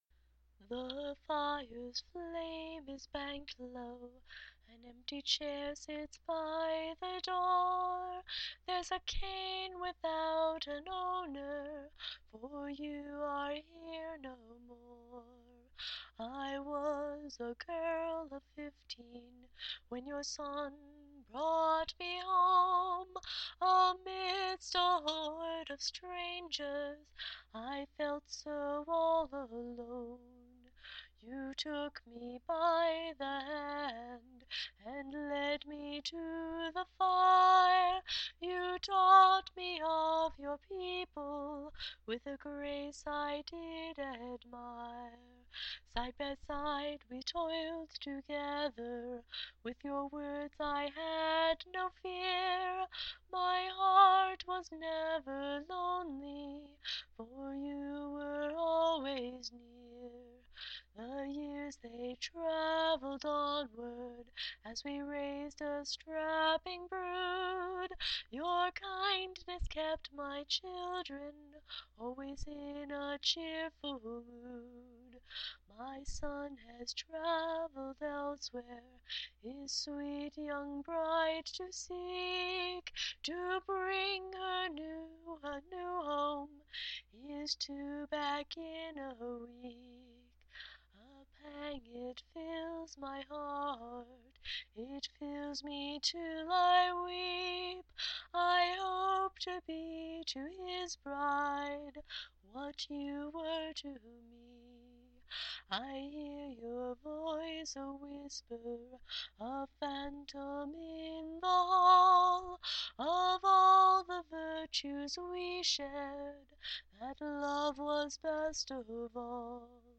Wistful.